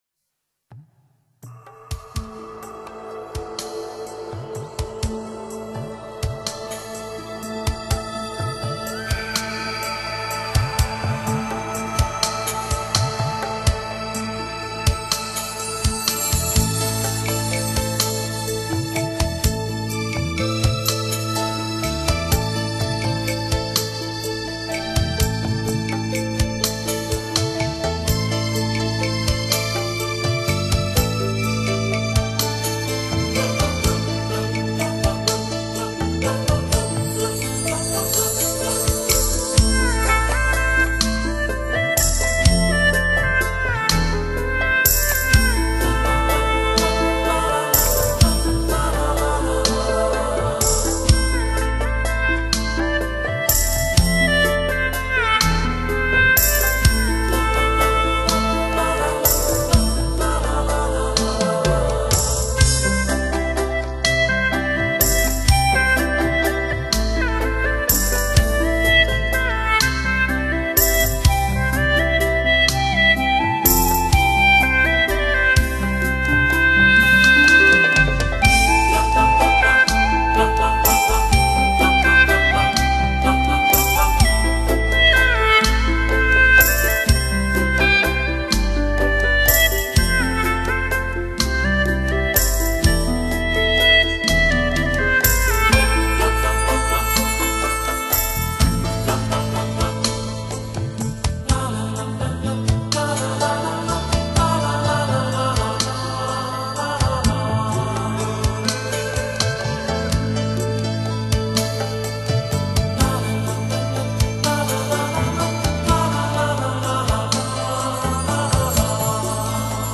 犹胜天籟的发烧民乐，因DTS环绕音效技术而显得韵味十足，声色动人。
采用国际最为先进的DTS环绕音效技术，得天独厚的优秀技术打造一张不可不听的发烧大碟。
发烧民乐，除了本身具备的极高的艺术价值之外，它更多的是一种自然原始的回归。